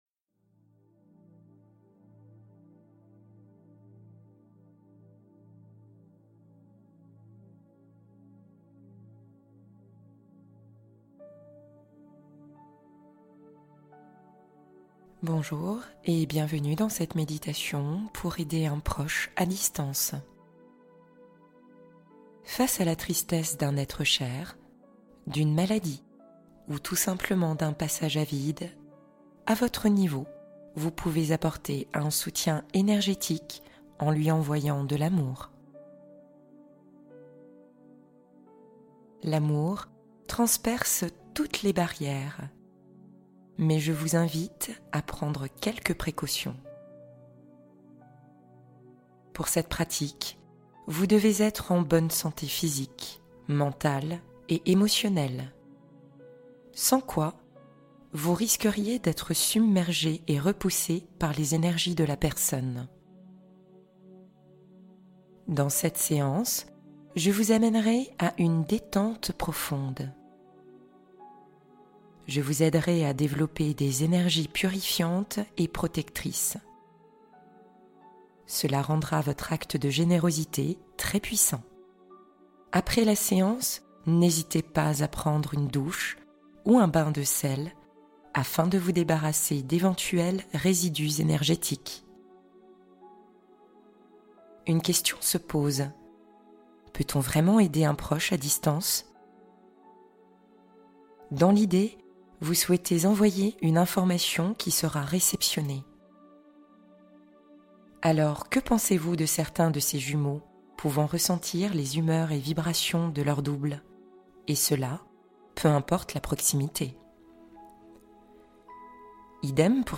Envoyez de l’amour et guérissez vos relations | Méditation énergétique